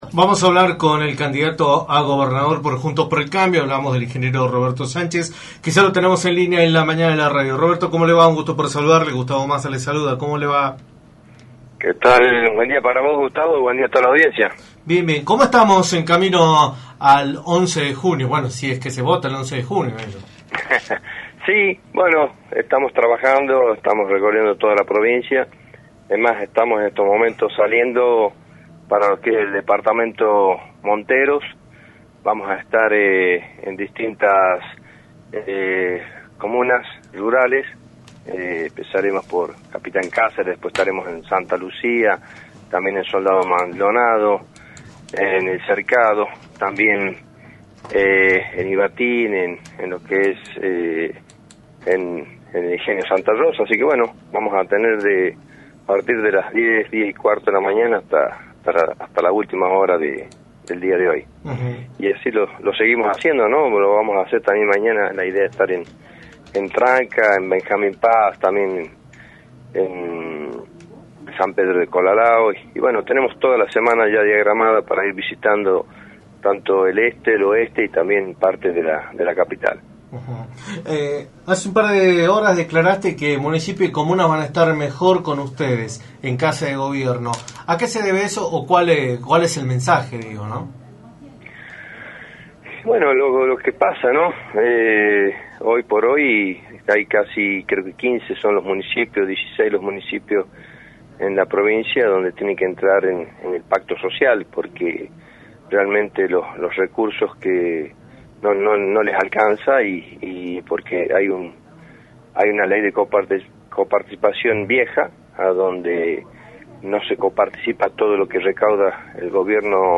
Roberto Sánchez, candidato a Gobernador por Juntos por el Cambio, analizó en Radio del Plata Tucumán, por la 93.9, el escenario político provincial, de cara a las elecciones del próximo 11 de junio. Sánchez informó los avances de su recorrido territorial y afirmó que los municipios y las comunas rurales prosperarán bajo su gestión.